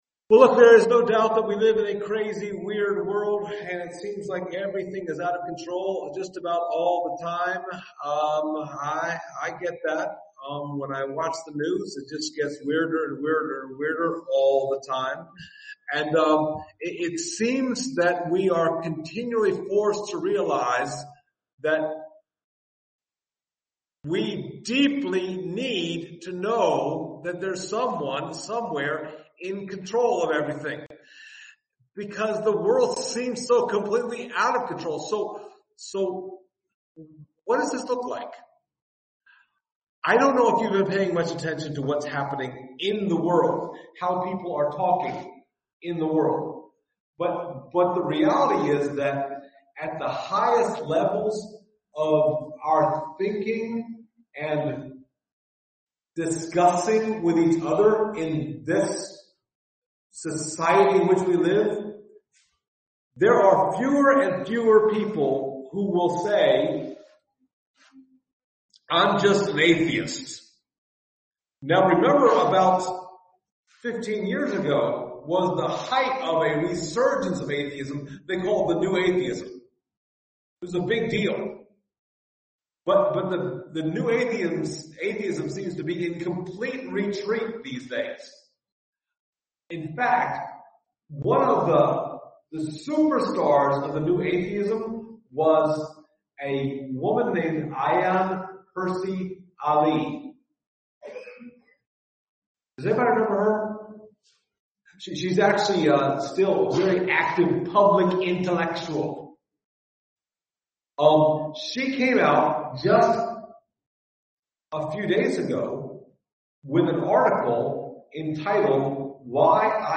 The Acts of the Holy Spirit Passage: Acts 4:23-31 Service Type: Sunday Morning « Should We Obey Man or God?